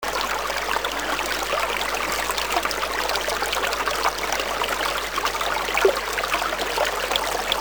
Cliquez sur l'image ci-dessous pour écouter le ruisseau